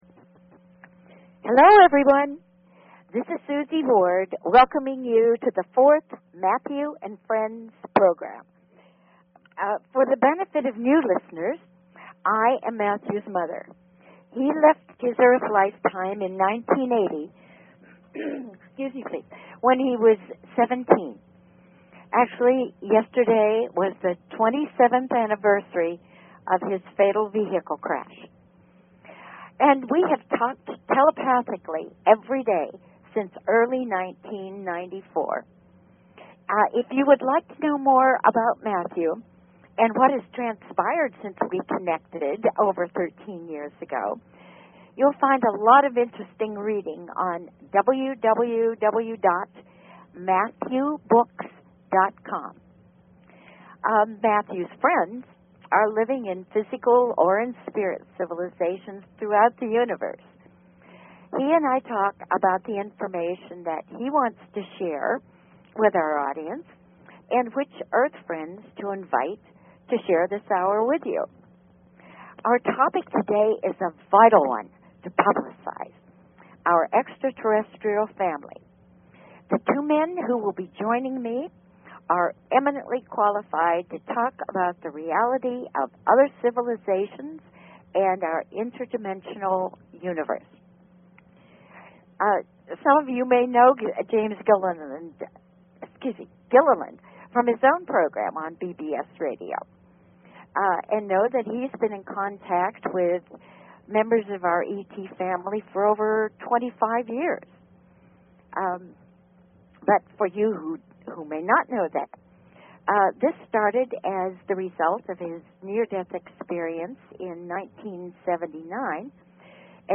Talk Show Episode, Audio Podcast, Matthew_and_Friends_Hour and Courtesy of BBS Radio on , show guests , about , categorized as